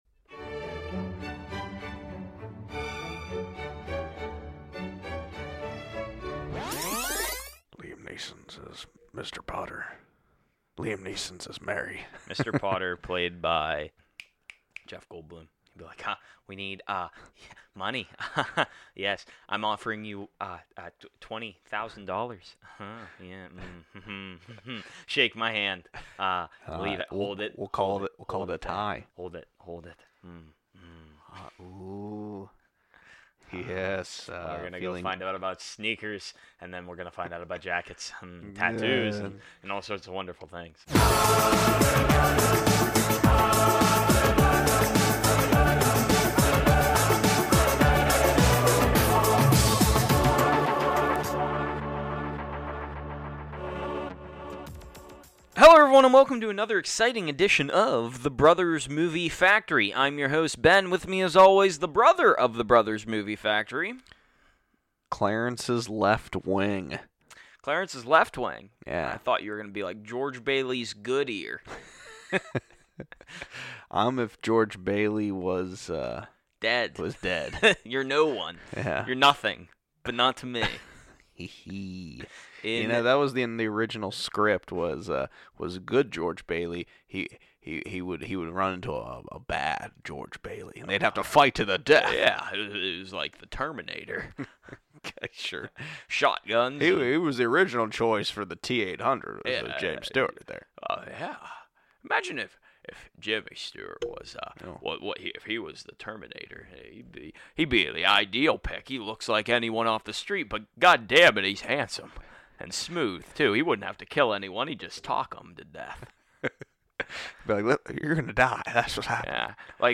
-Jimmy Stewart Impressions!!